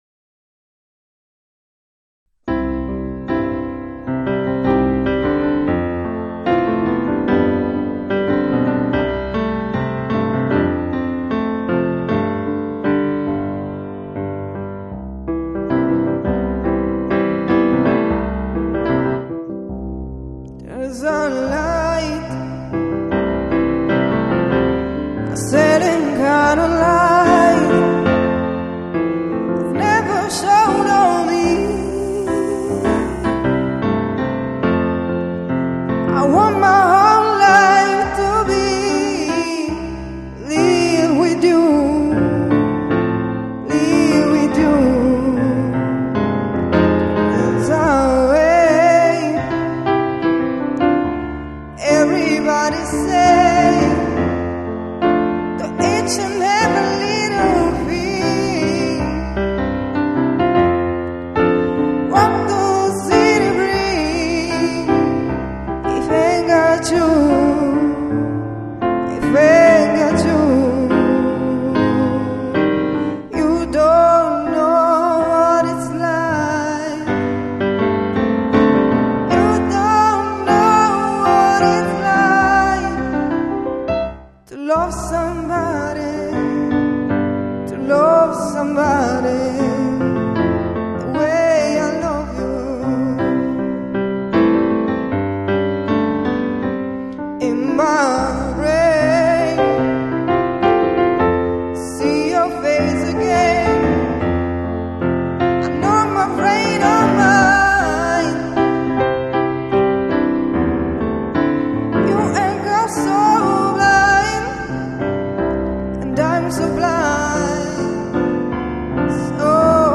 Voce e Pianoforte
cover